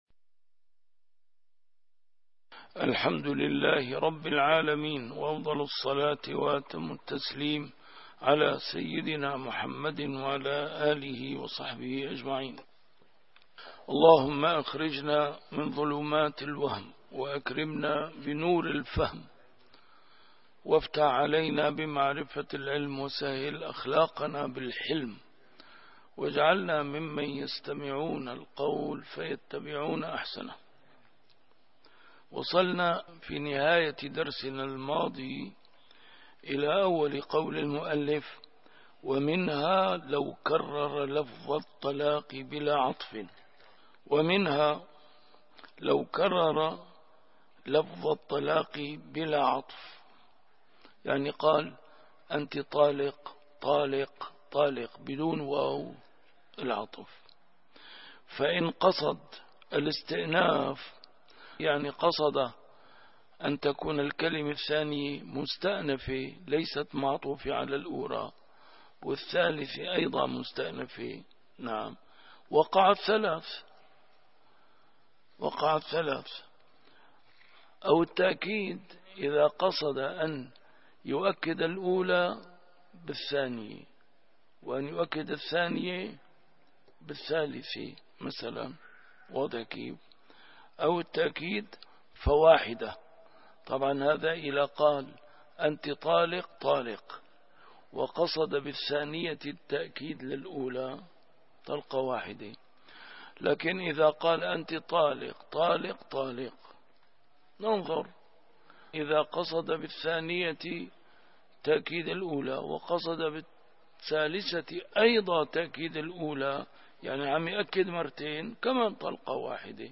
كتاب الأشباه والنظائر للإمام السيوطي - A MARTYR SCHOLAR: IMAM MUHAMMAD SAEED RAMADAN AL-BOUTI - الدروس العلمية - القواعد الفقهية - كتاب الأشباه والنظائر، الدرس الخامس والعشرون: في شروط النية.